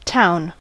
town: Wikimedia Commons US English Pronunciations
En-us-town.WAV